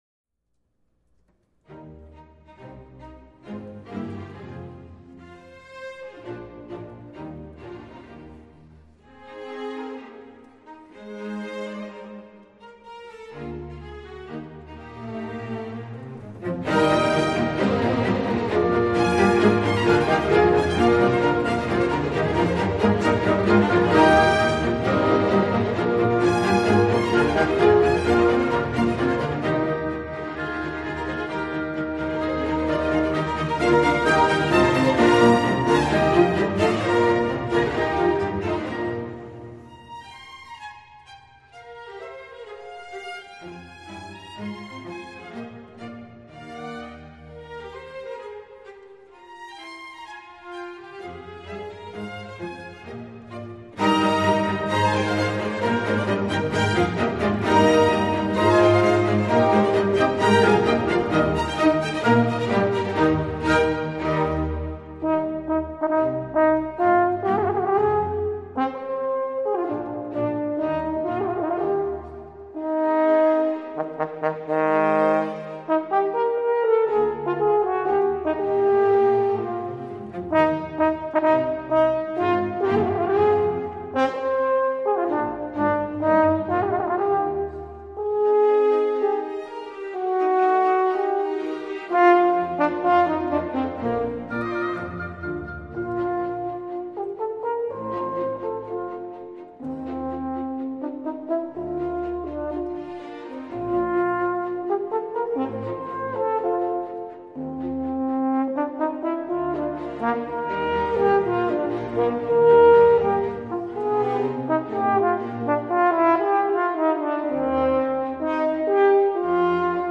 Classical Crossover Лейбл